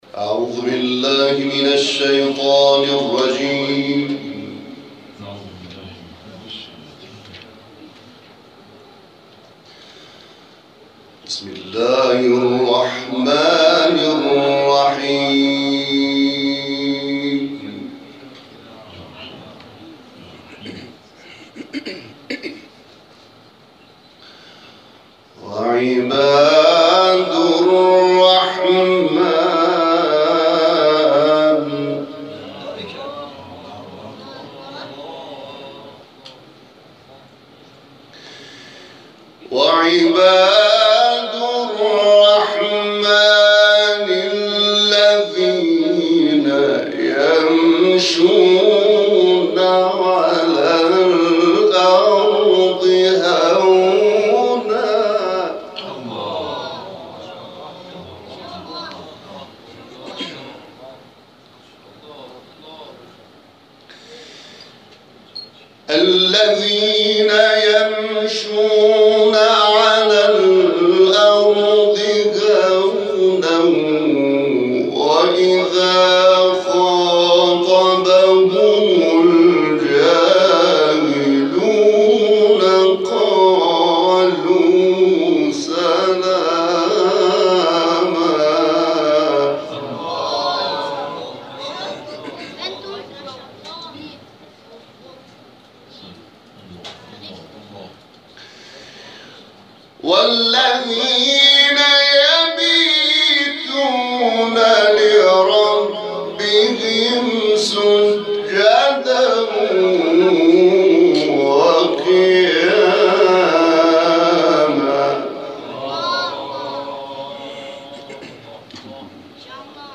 در آستان امامزادگان پنج تن لویزان برگزار شد؛
تلاوت